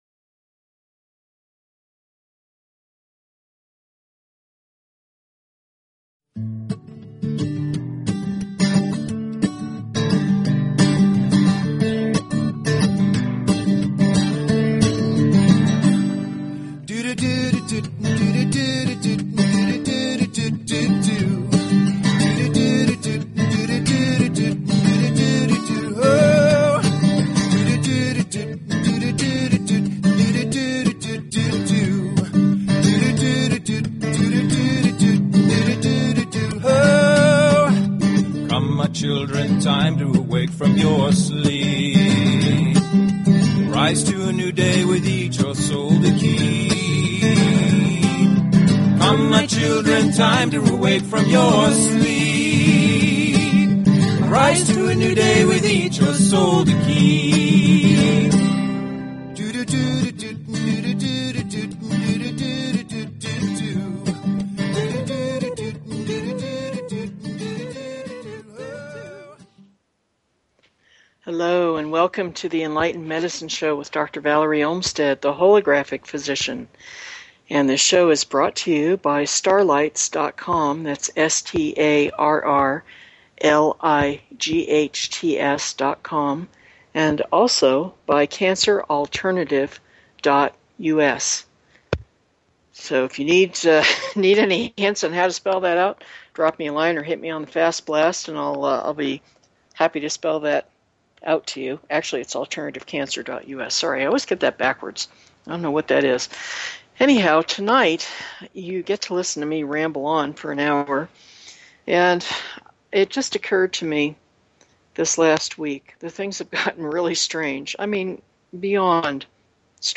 Show Headline Enlightened_Medicine Show Sub Headline Courtesy of BBS Radio Enlightened Medicine - October 4, 2012 Enlightened Medicine Please consider subscribing to this talk show.